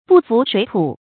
注音：ㄅㄨˋ ㄈㄨˊ ㄕㄨㄟˇ ㄊㄨˇ
不服水土的讀法